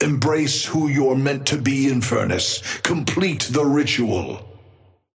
Amber Hand voice line - Embrace who you're meant to be, Infernus.
Patron_male_ally_inferno_start_05.mp3